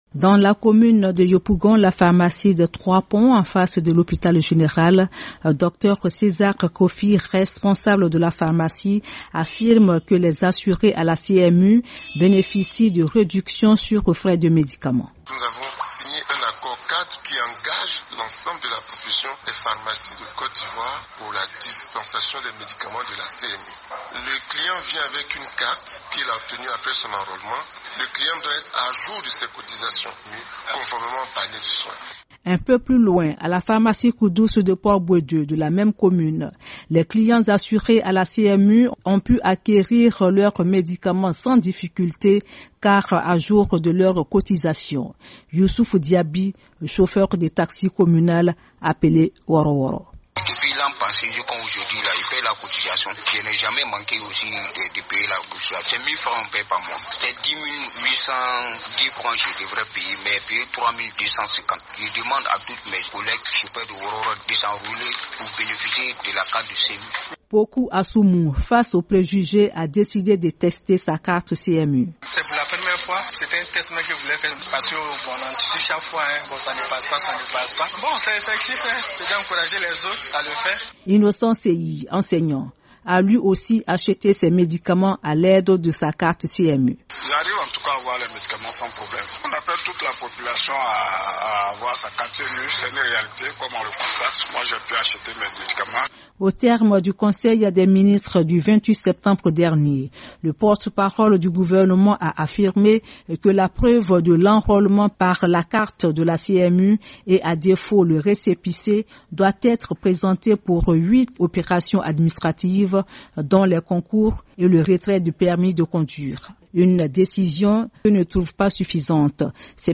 En Côte d’Ivoire le gouvernement a opté pour la Couverture maladie universelle. Il veut garantir le déploiement complet du système de couverture sociale. Le reportage